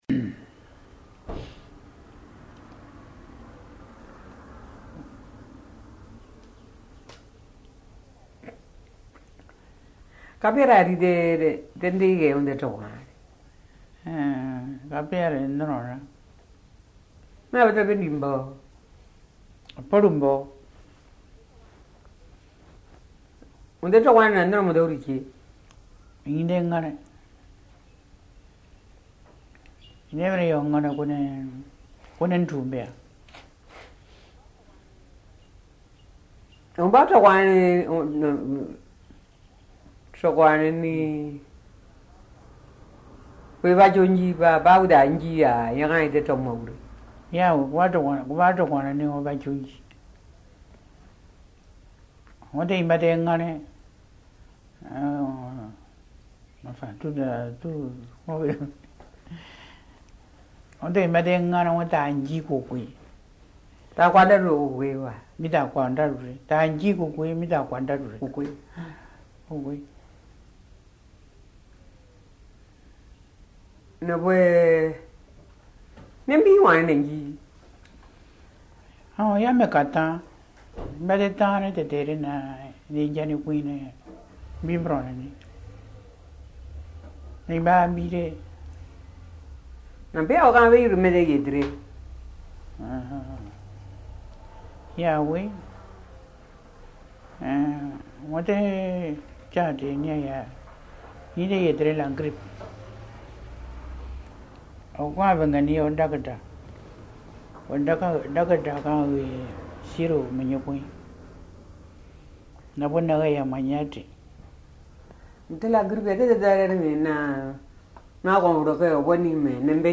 Documents joints Dialogue leçon 20 ( MP3 - 2.1 Mio ) Un message, un commentaire ?